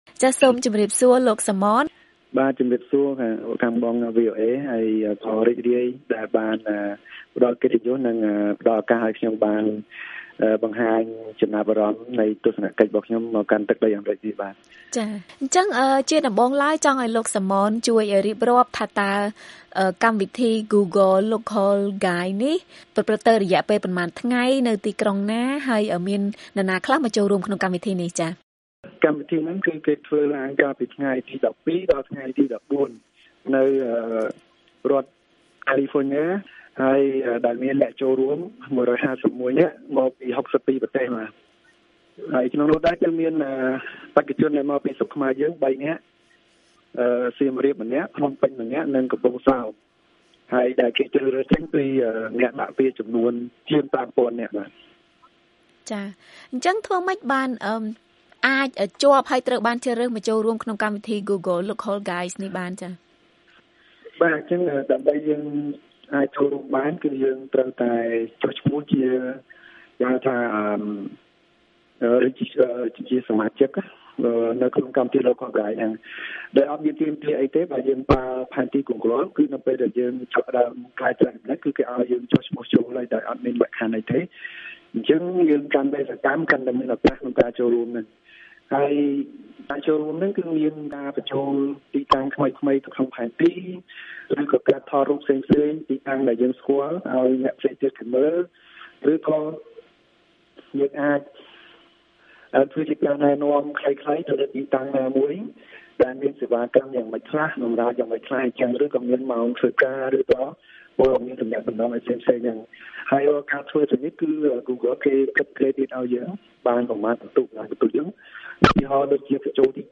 បទសម្ភាសន៍VOA៖ អត្ថប្រយោជន៍នៃការធ្វើការងារស្ម័គ្រចិត្តក្នុងវិស័យបច្ចេកវិទ្យា
តាមរយៈទូរស័ព្ទ